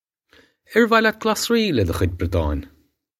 Pronunciation for how to say
Air vah lyat glos-ree leh duh khwidge brodd-aw-in?